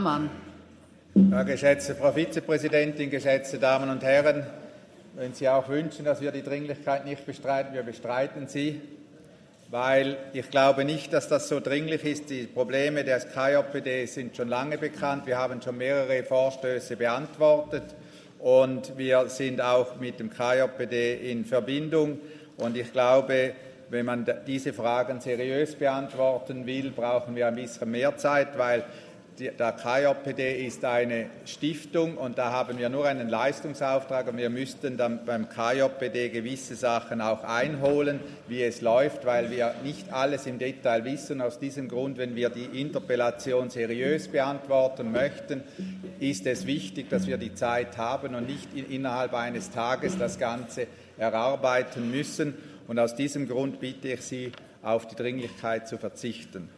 28.11.2023Wortmeldung
Session des Kantonsrates vom 27. bis 29. November 2023, Wintersession